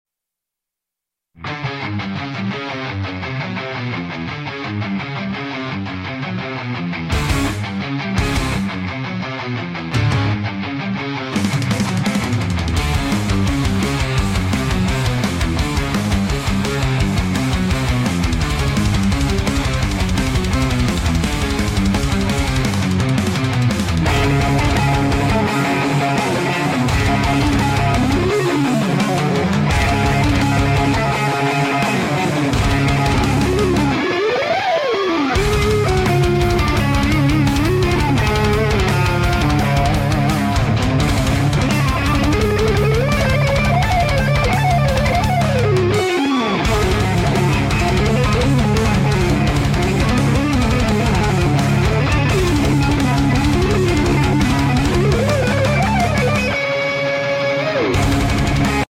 solo gitar
saya memakai gitar Fender Stratocaster fret Scalloped model dan saya memakai efek valeton GP 200